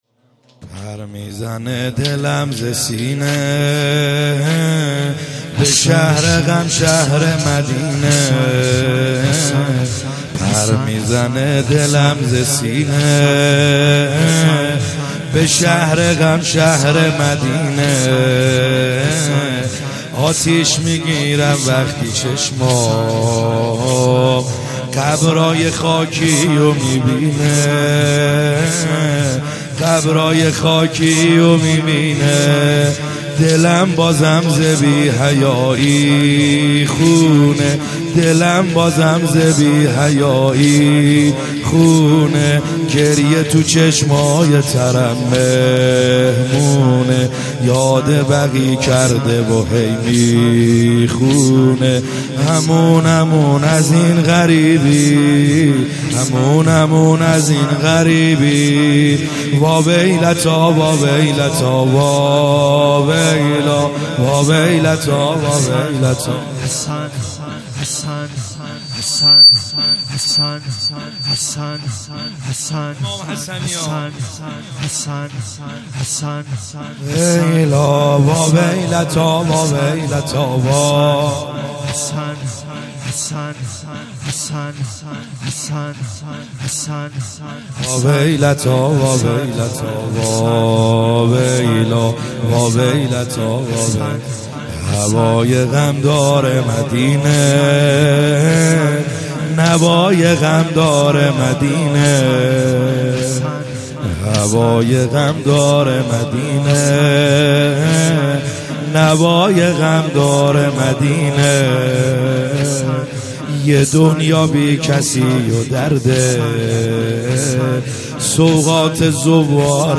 خیمه گاه - بیرق معظم محبین حضرت صاحب الزمان(عج) - زمینه | پر میزنه دلم زسینه